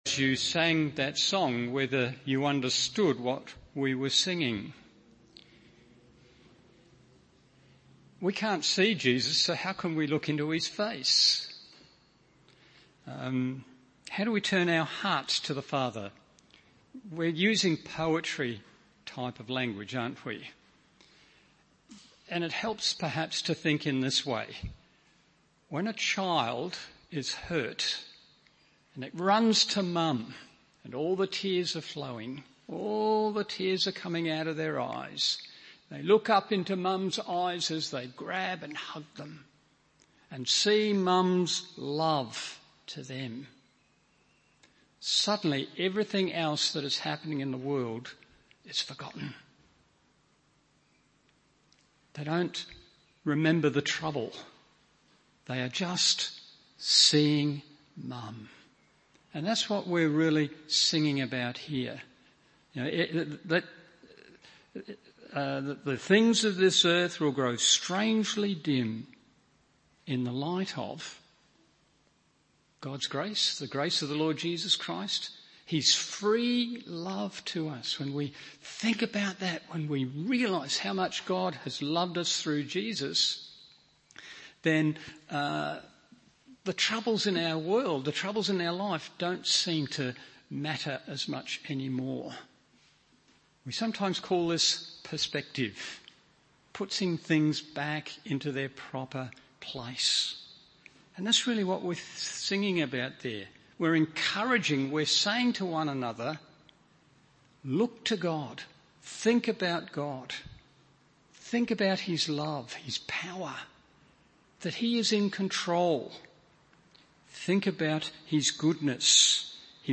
Evening Service Meeting Jesus